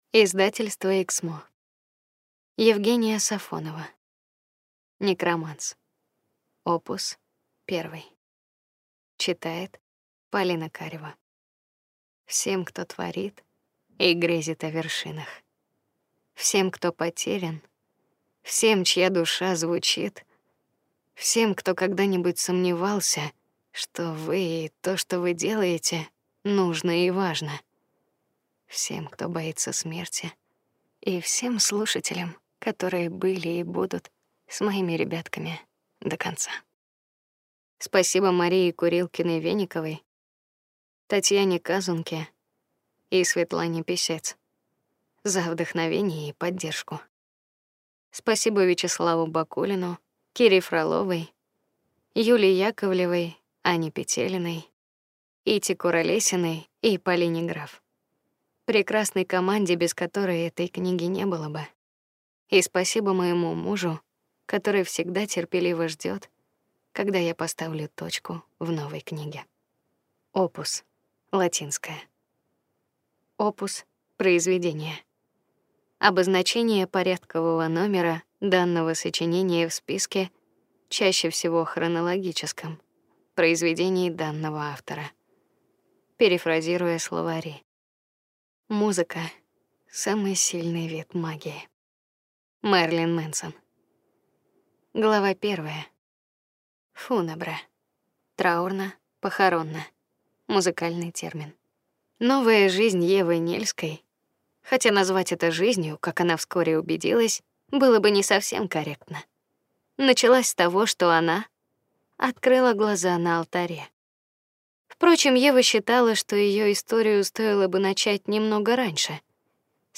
Аудиокнига Некроманс. Opus 1 | Библиотека аудиокниг